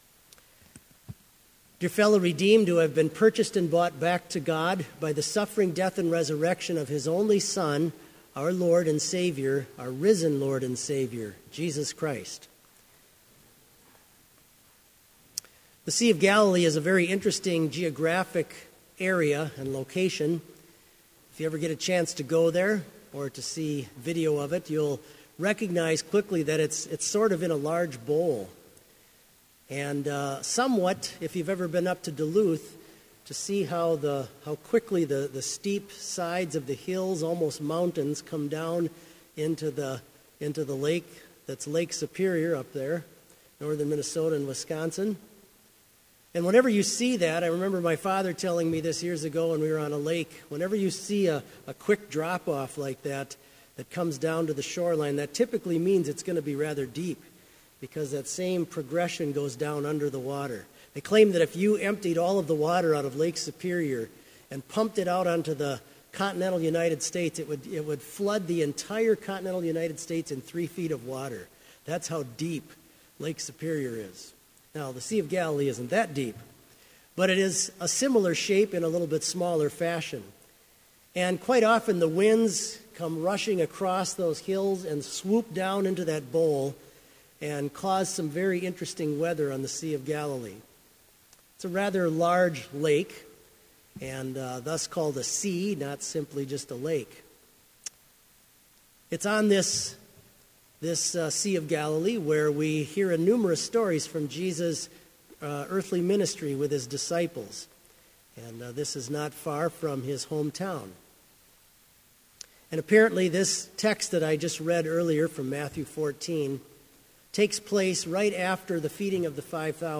Sermon audio for Evening Vespers - April 6, 2016